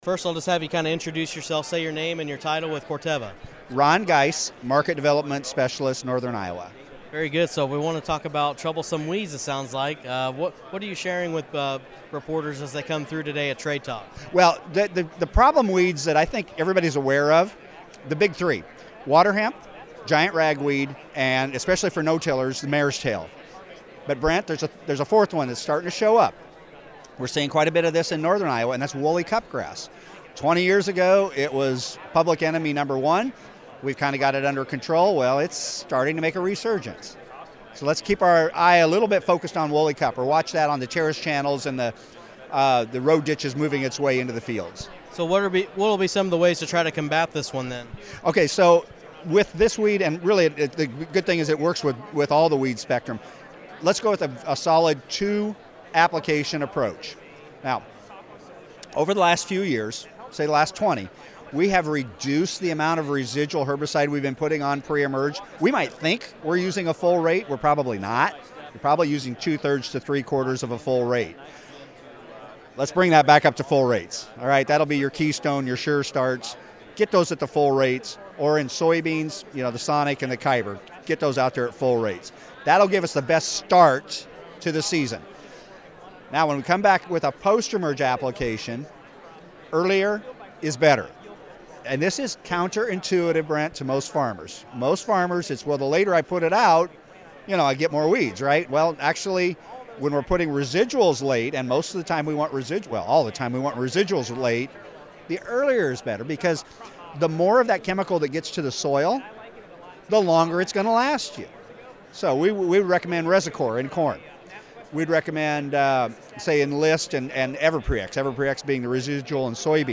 AUDIO REPORT: Controlling Woolly Cupgrass with Corteva Agriscience